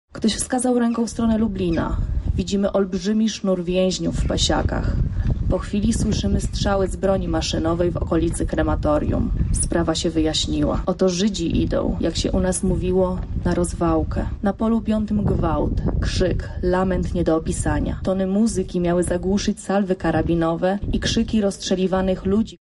Z tej okazji pracownicy Muzeum na Majdanku upamiętnili te wydarzenia między innymi odczytując wybrane relacje świadków „krwawej środy”.